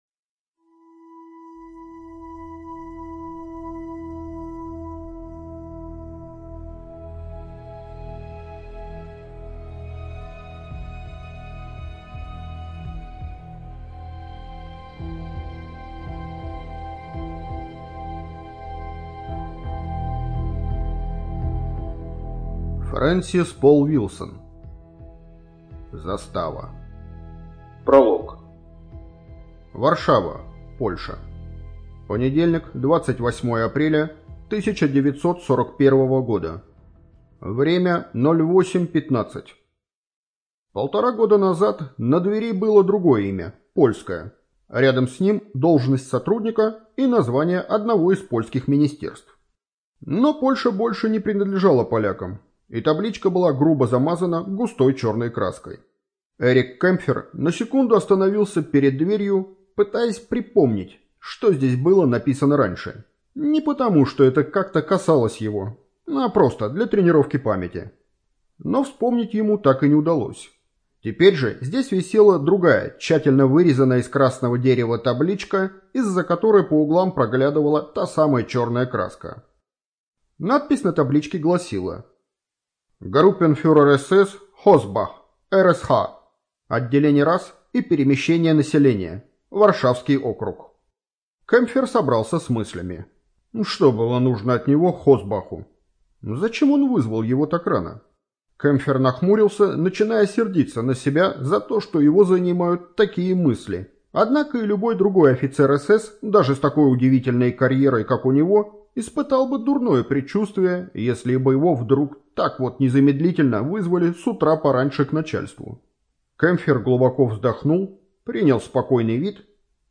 ЖанрУжасы и мистика